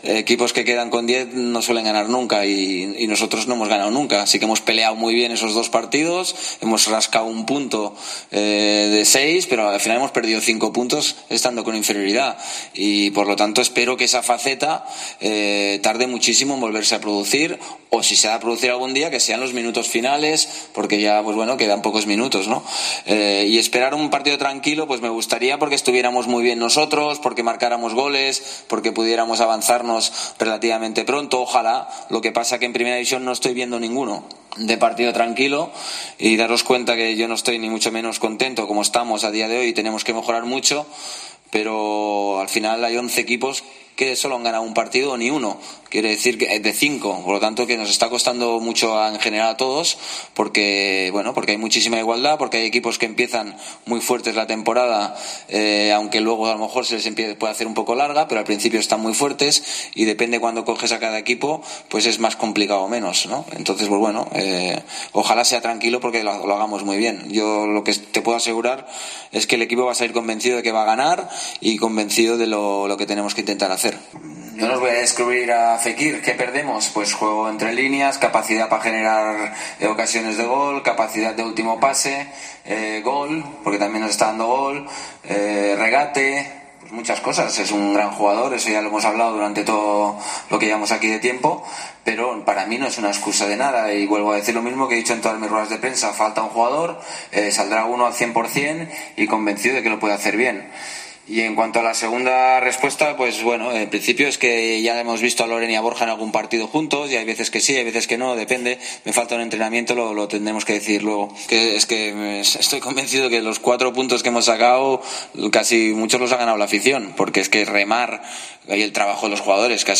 Rubi en rueda de prensa: la lesión de Fekir y la visita del Levante